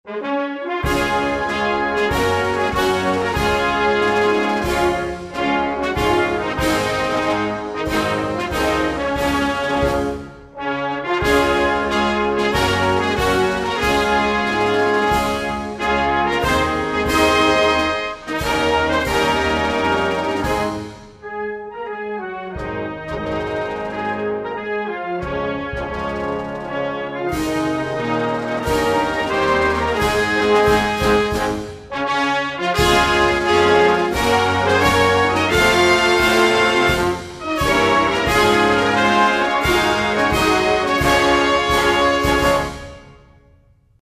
instrumental-national-anthem-Thailand.mp3